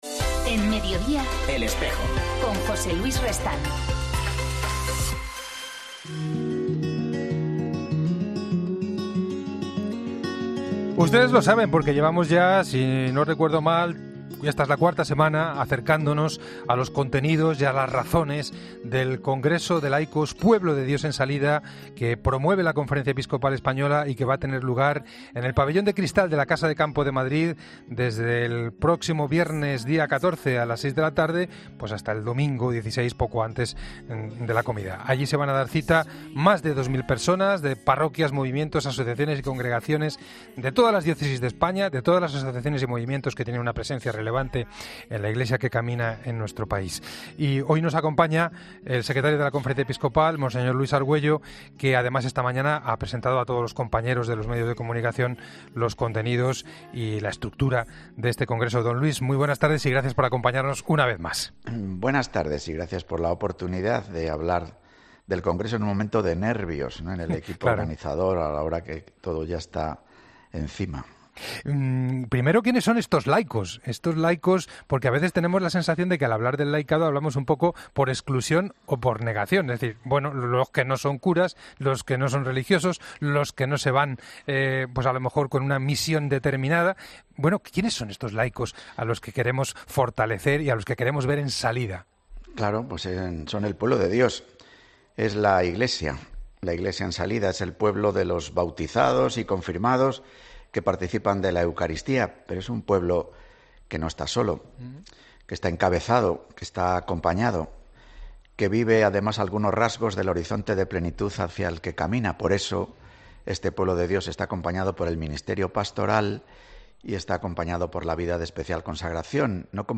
En el umbral del Congreso de Laicos "Pueblo de Dios en salida" hablamos con el Secretario General de la Conferencia Episcopal, Mons. Luis Argüello.